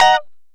Lng Gtr Chik Min 11-D#3.wav